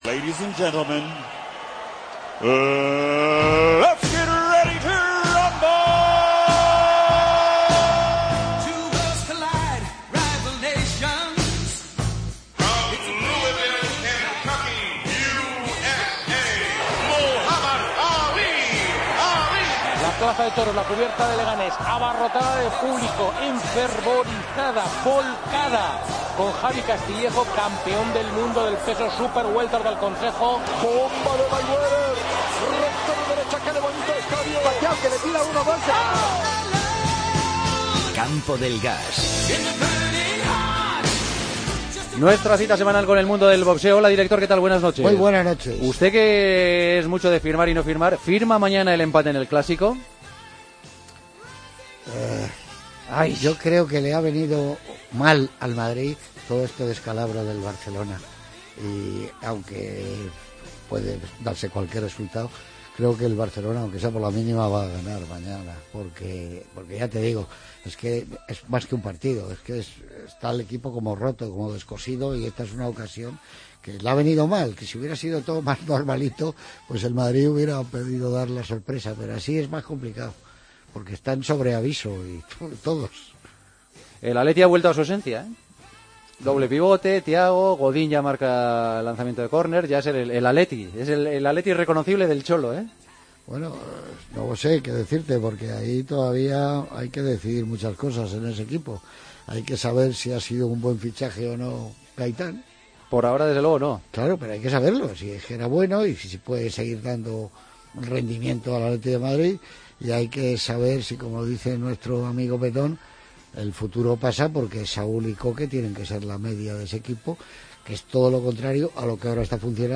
hablan cada viernes de boxeo en El Partidazo. Analizamos el combate entre Lomachenko y Walters.